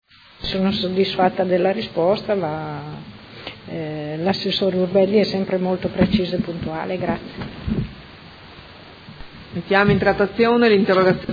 Seduta del 22/11/2018. Conclude interrogazione della Consigliera Santoro (Lega Nord) avente per oggetto: Microaree nomadi